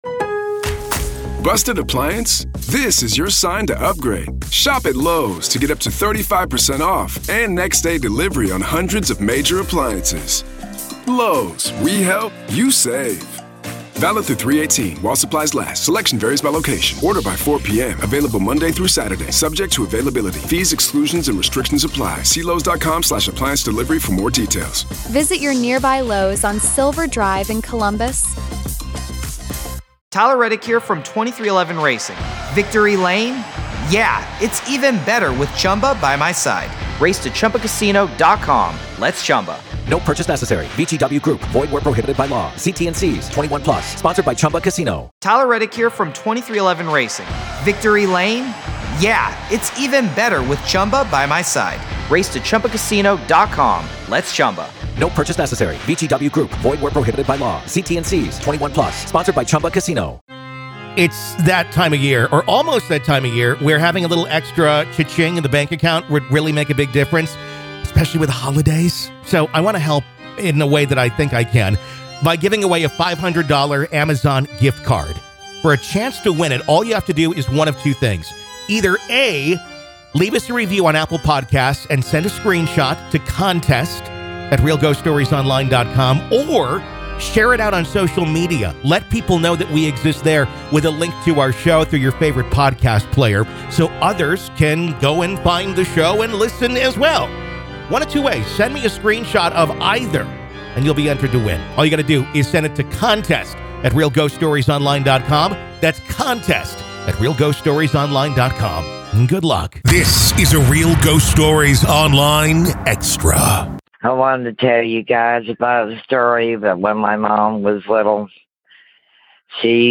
Haunting real ghost stories told by the very people who experienced these very real ghost stories.